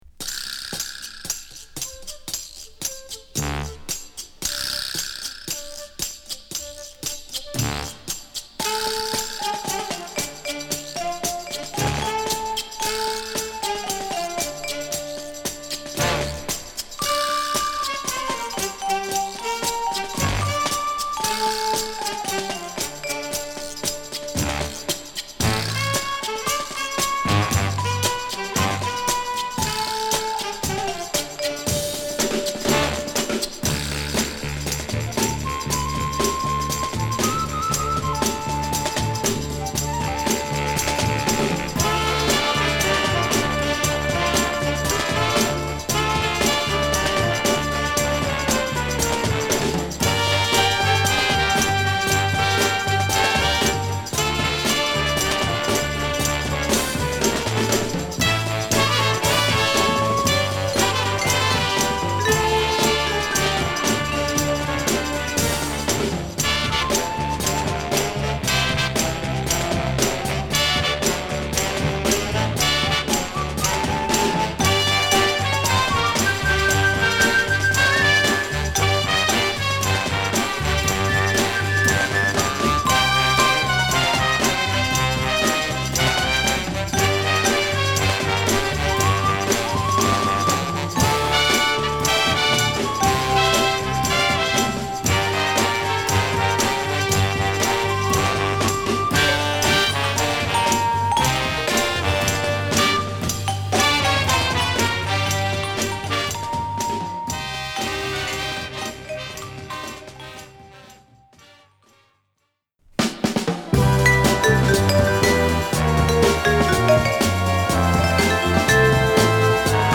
Home > Big Band